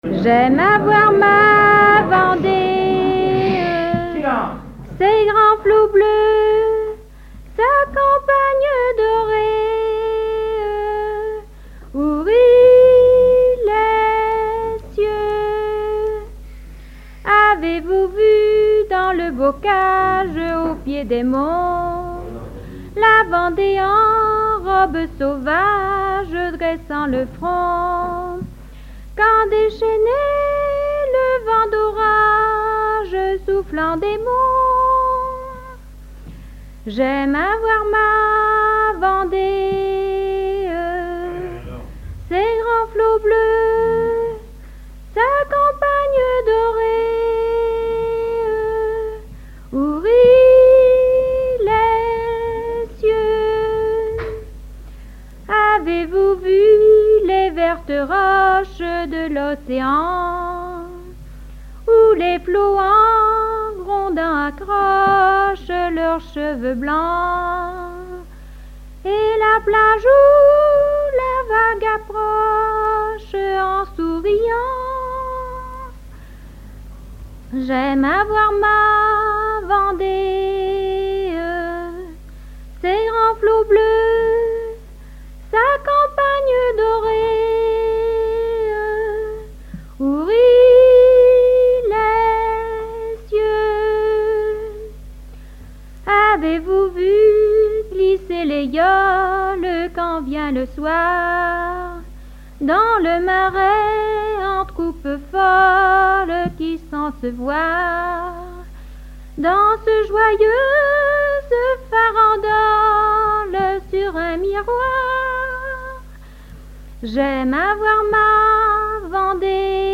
à la salle d'Orouët
Pièce musicale inédite